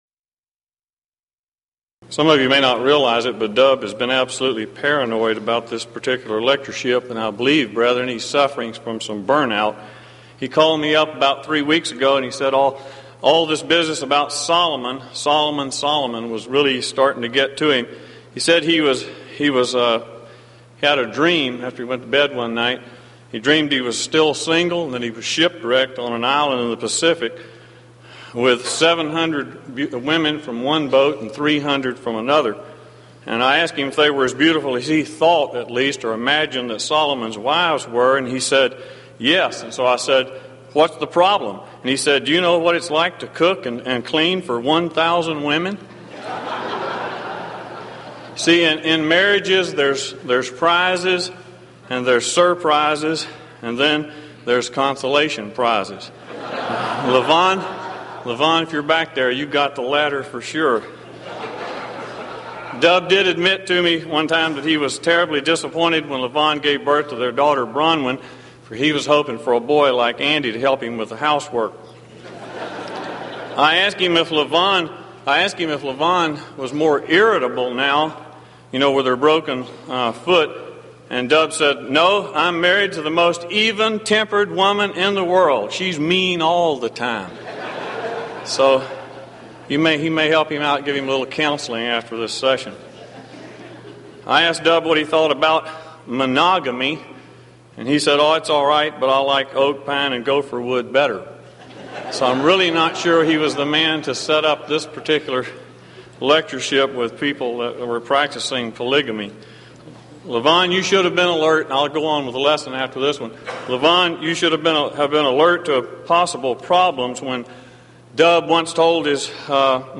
Series: Denton Lectures